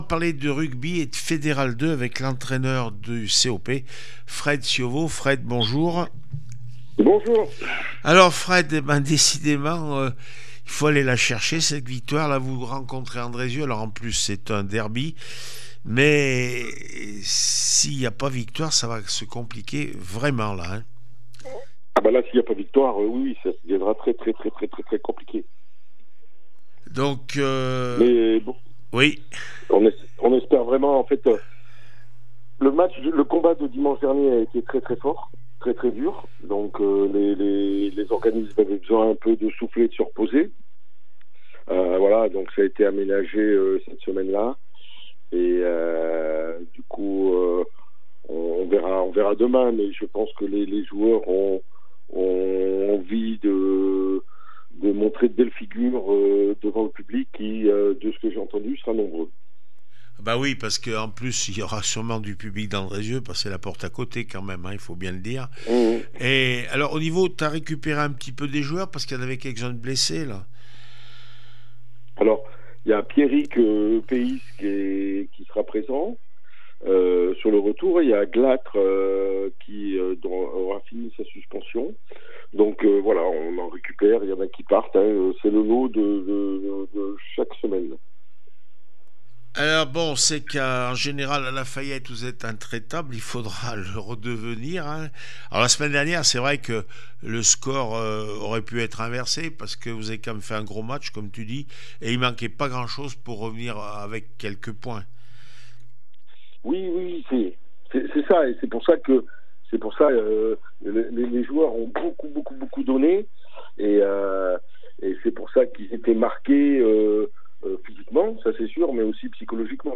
22 mars 2025   1 - Sport, 1 - Vos interviews